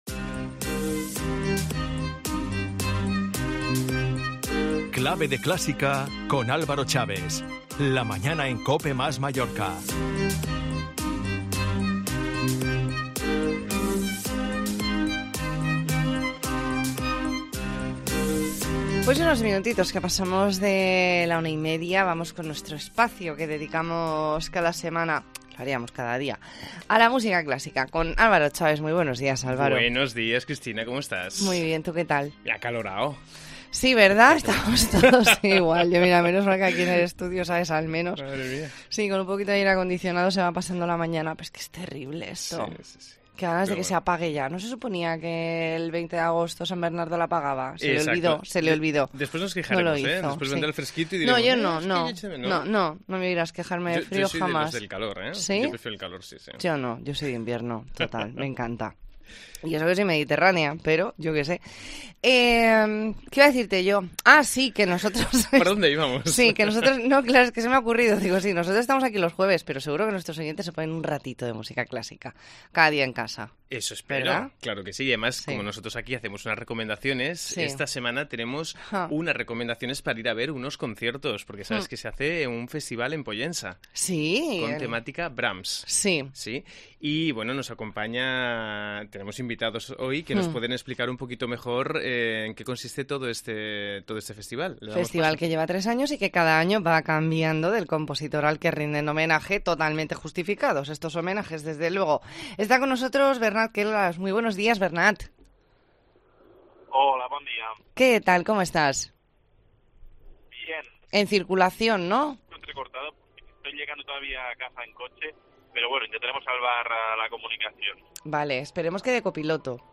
E ntrevista en La Mañana en COPE Más Mallorca, jueves 8 de septiembre de 2022.